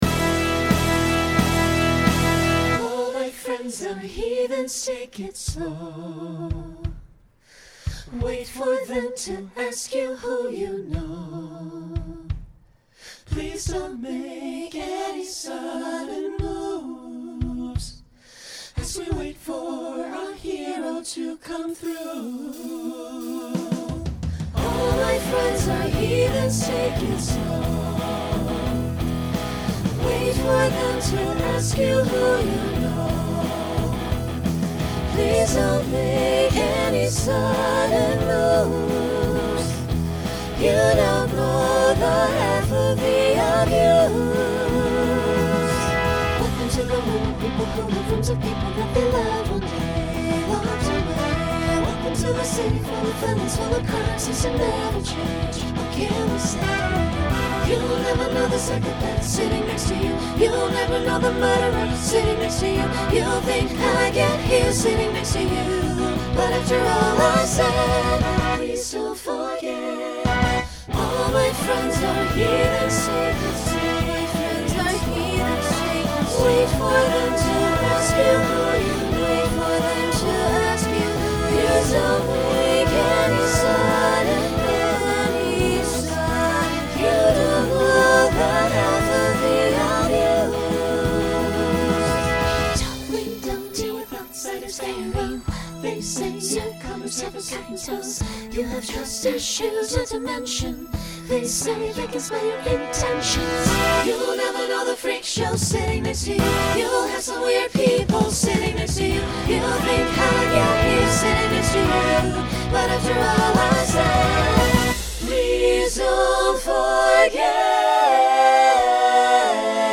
Genre Rock
Mid-tempo Voicing SATB